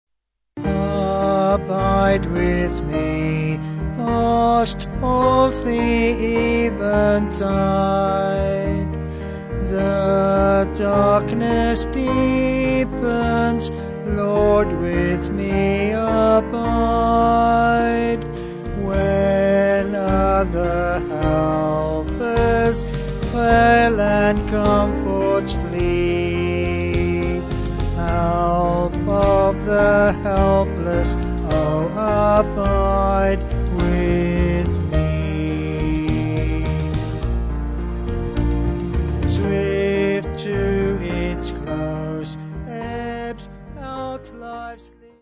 Vocals and Band
265kb Sung Lyrics 3.4mb